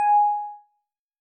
Techmino/media/sample/bell/24.ogg at 940ac3736cdbdb048b2ede669c2e18e5e6ddf77f
添加三个简单乐器采样包并加载（之后用于替换部分音效）